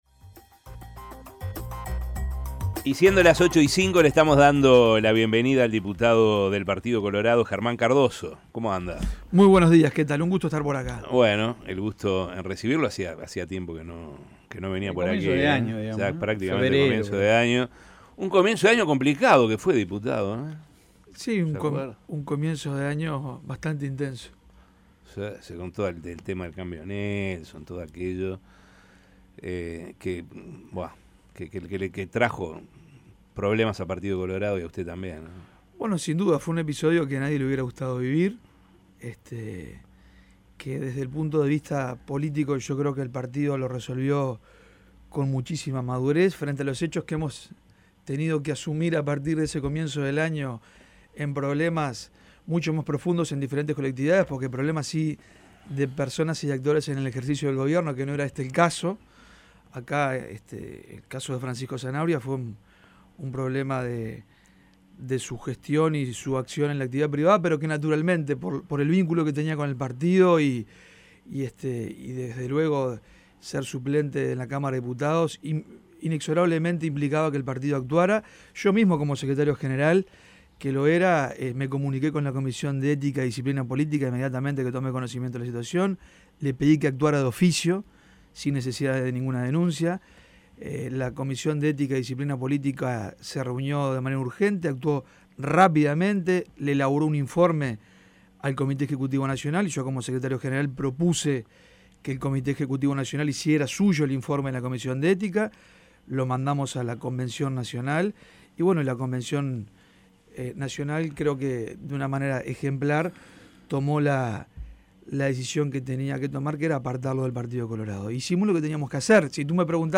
El diputado colorado Germán Cardoso aseguró a La Mañana de El Espectador que se presentará como precandidato a la presidencia de la República en la interna partidaria, por el sector Vamos Uruguay.
Escuche la entrevista de La Mañana: